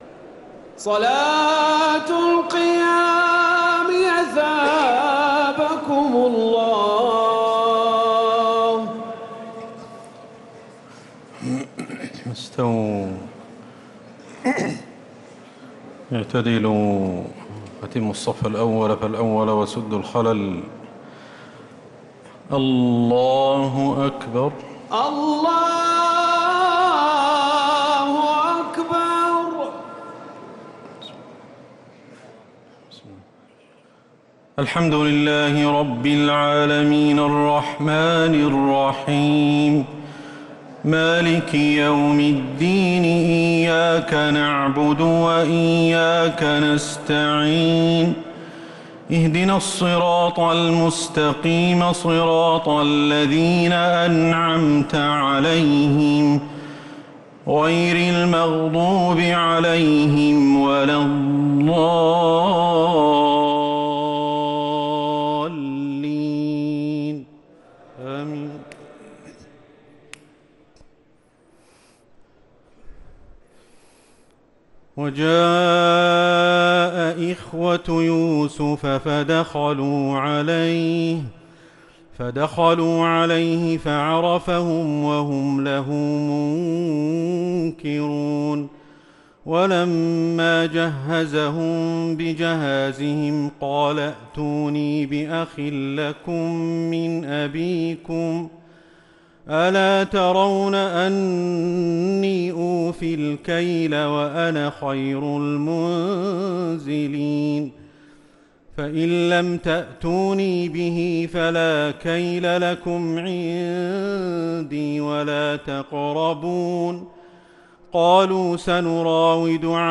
تراويح ليلة 17 رمضان 1446هـ من سورتي يوسف (58-111) و الرعد (1-18) | Taraweeh 17th night Ramadan 1446H Surat Yusuf and Ar-Rad > تراويح الحرم النبوي عام 1446 🕌 > التراويح - تلاوات الحرمين